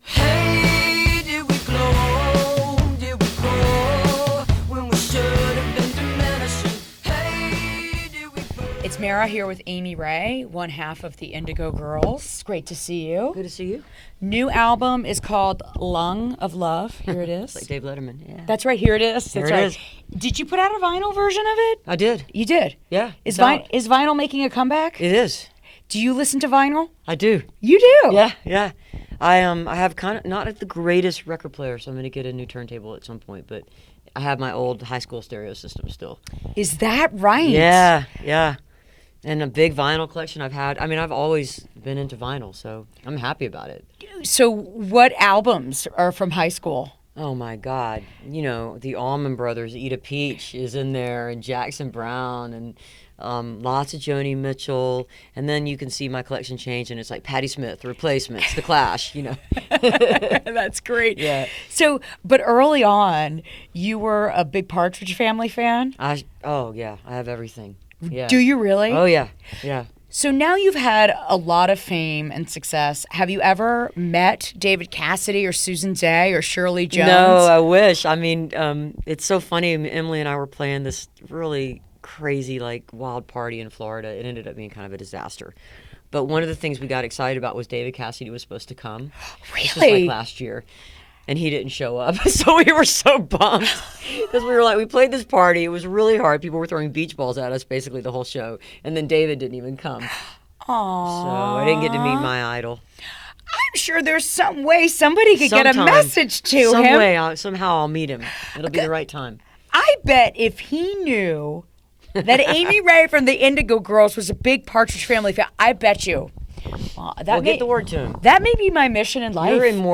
01. interview (8:03)